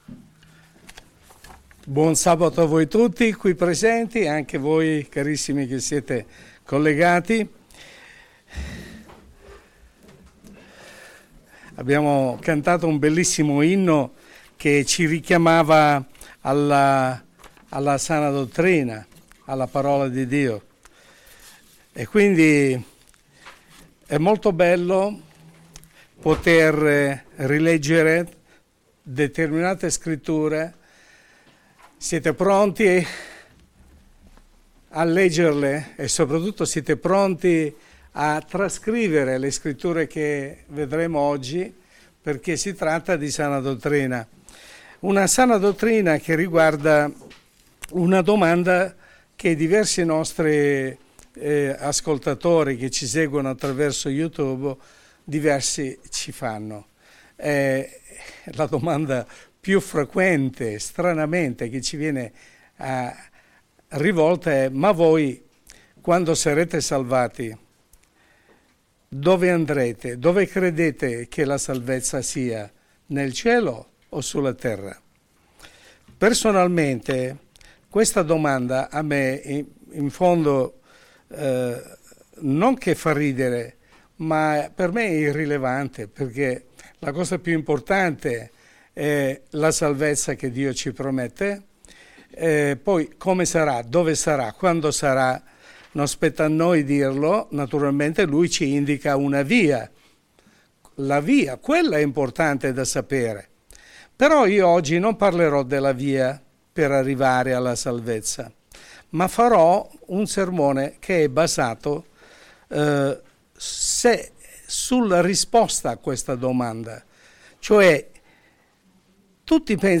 Ma la Parola di Dio, la Bibbia, che cosa dice a riguardo? (Messaggio pastorale